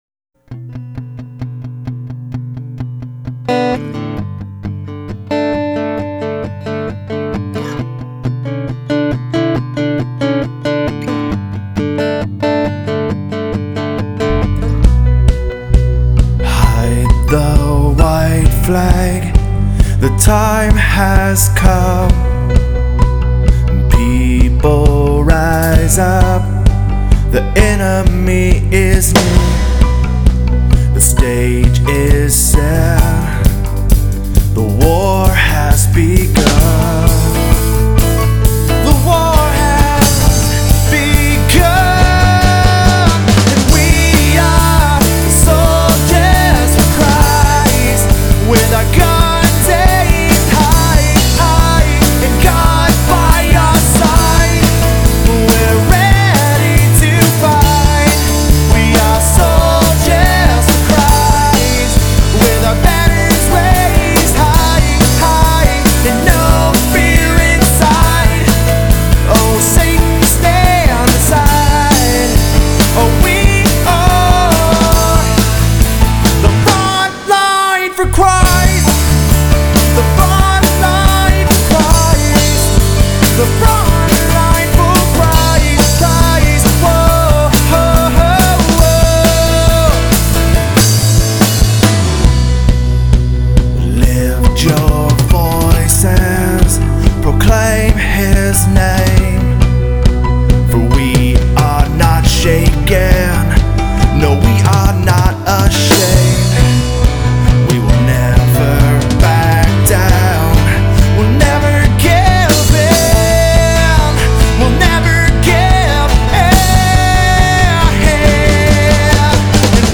recorded at Shoreline Community Church